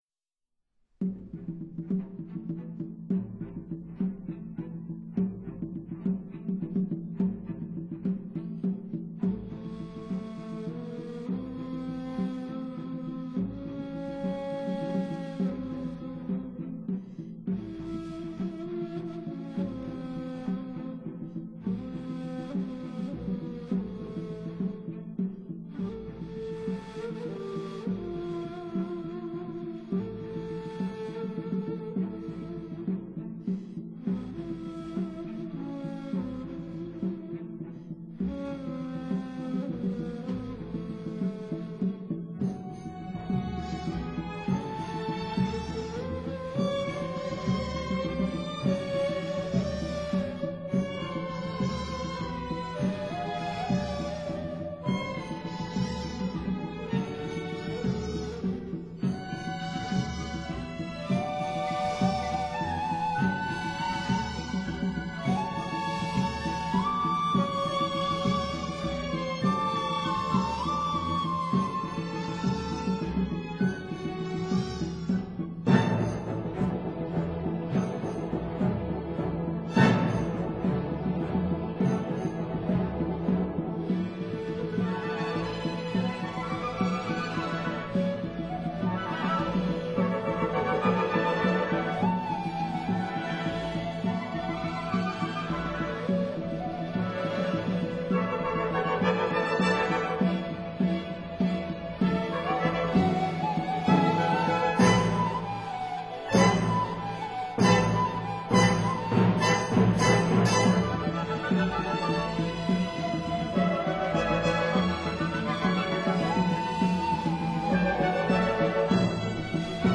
ney